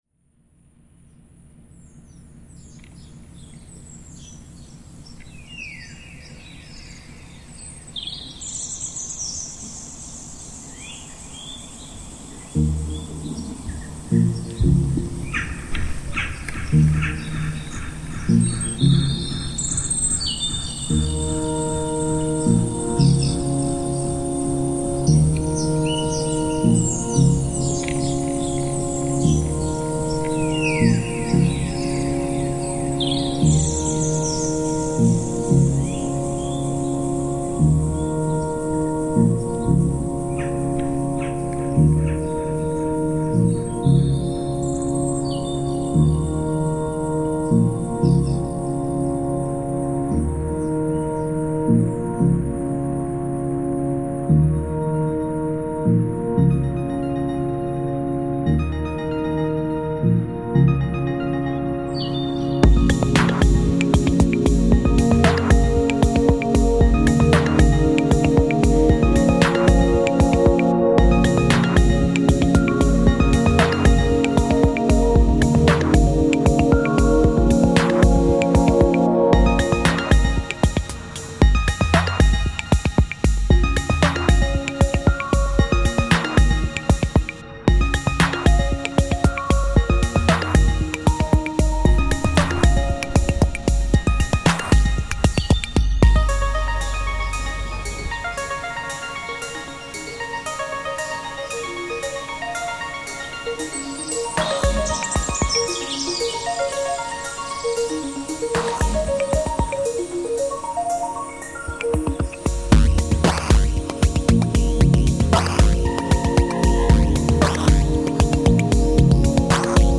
The main synth riff is bird notes converted to melody."